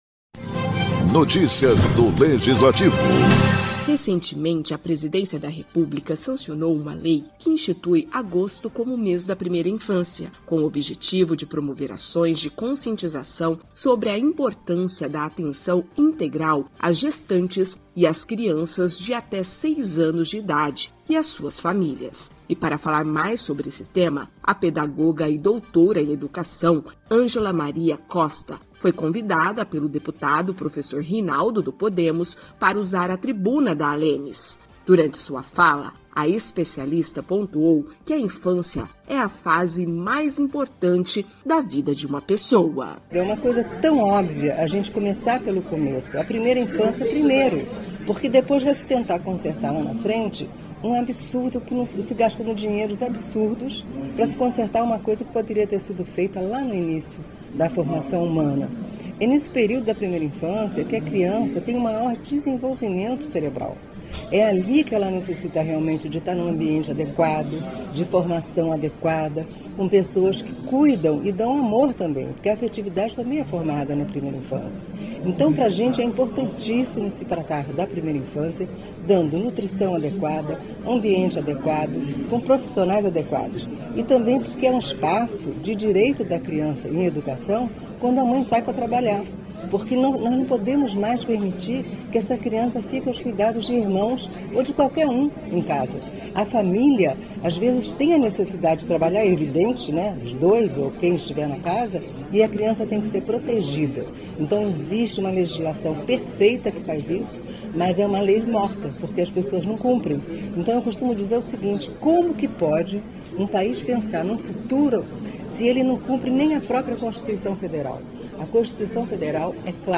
Durante sessão, especialista fala da importância dos cuidados na primeira infância